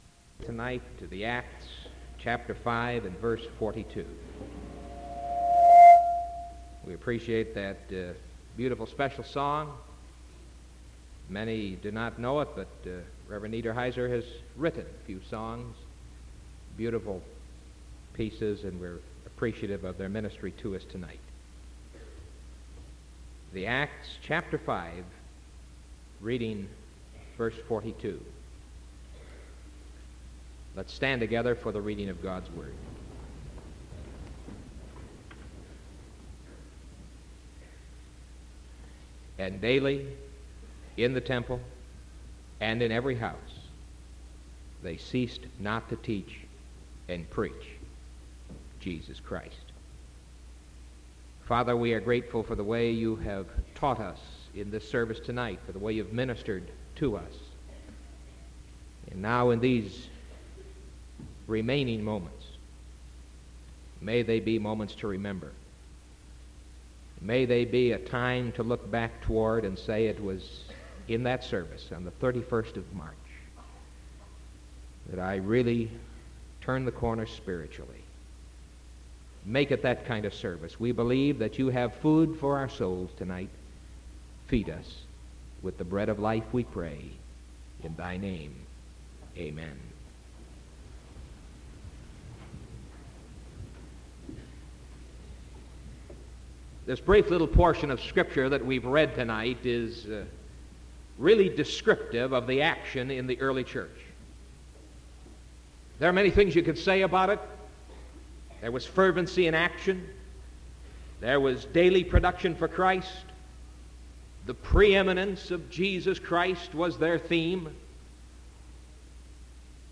Sermon from March 31st 1974 PM